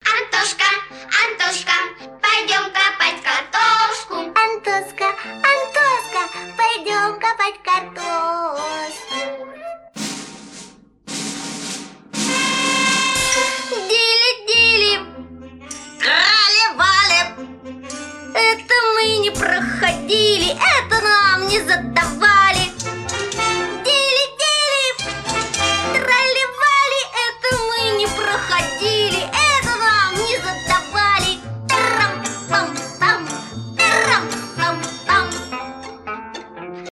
детская музыка
из мультфильмов